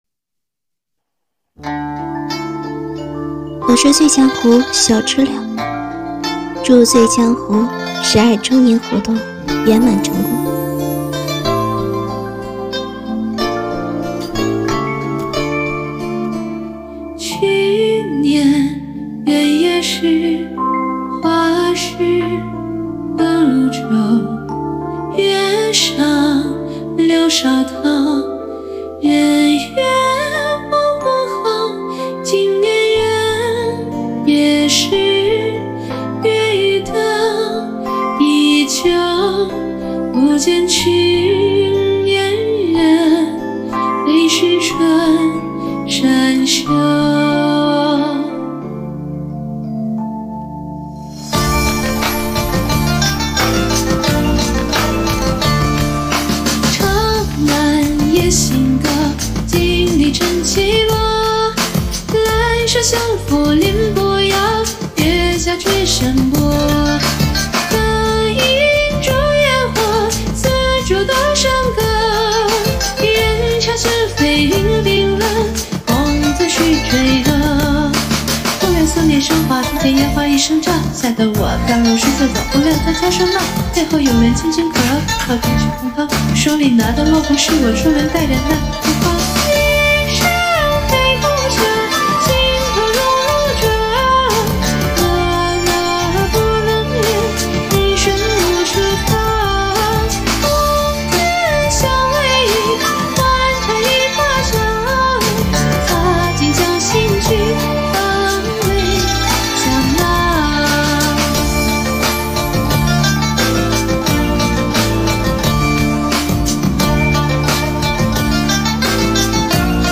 注册账号 × 如果无法播放，请点击此处在新窗口打开 “醉江湖十二周年庆”，首选标签便是古风、欢快、喜庆。
虽说歌词略带稍许哀愁，但是经过改编，歌曲加入了一些现代流行音乐的元素，如说唱部分，使得整首歌曲既有古典韵味又不失时尚感。